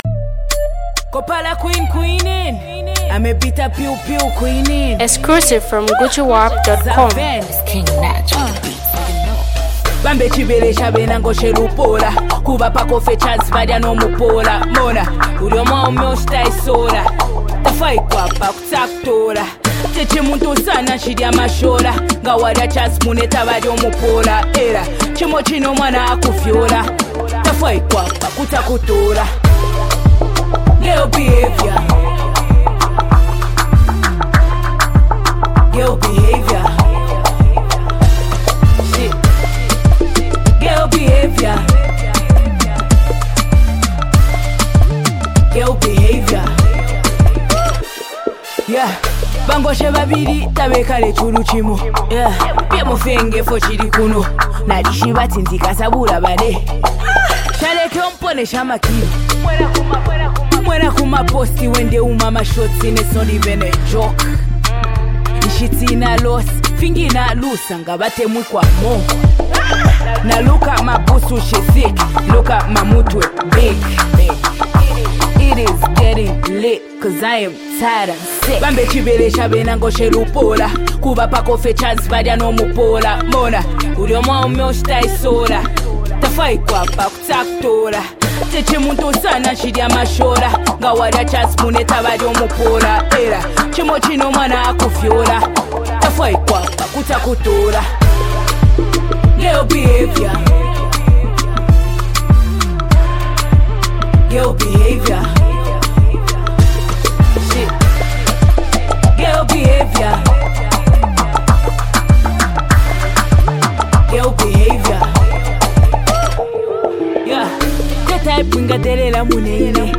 The Zambian talented female rapper